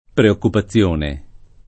preoccupazione [ preokkupa ZZL1 ne ] s. f.